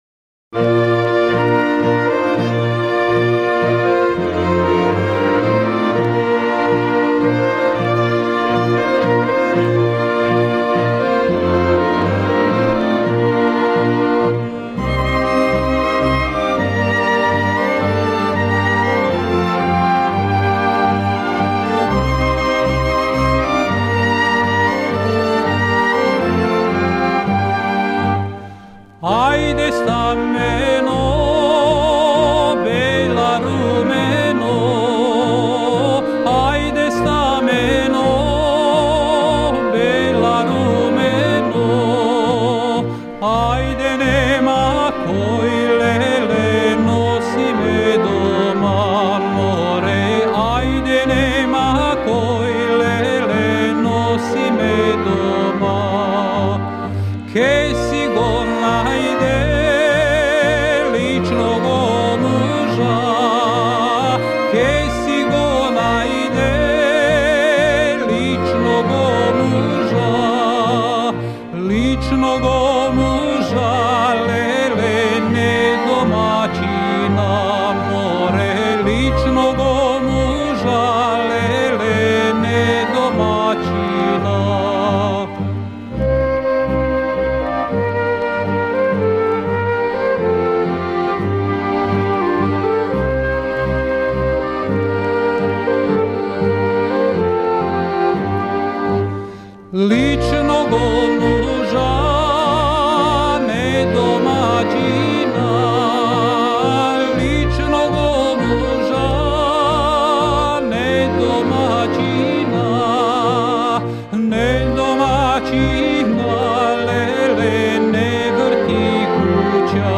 Порекло песме: Призренска Гора, Косово и Метохија
Напомена: Шаљива песма коју певају Горанци на састанцима у дому испрошене девојке или вереног момка. Ови се састанци одржавају сваке вечери четири, пет или шест недеља пред венчање.